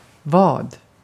Ääntäminen
IPA: /vɑː(d)/